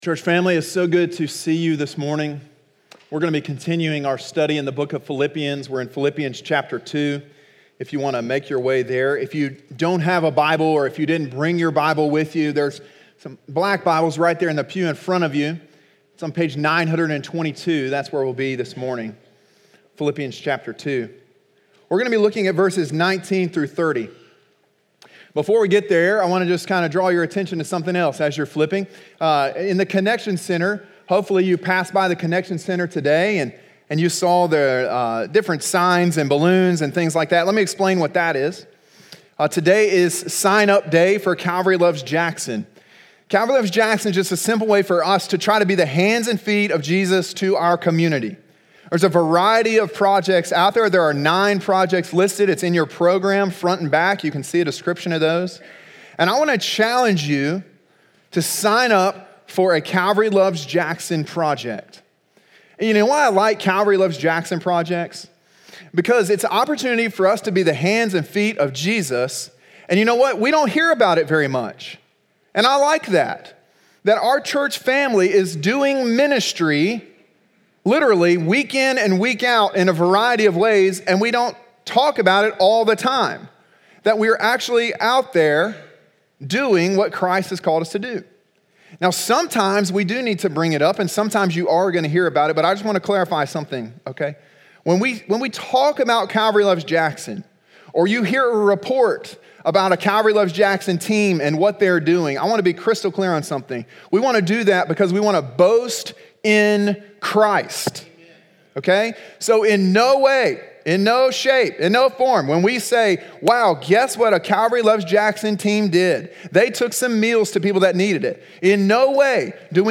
Sermon: “Model Servants” (Philippians 2:19-30) – Calvary Baptist Church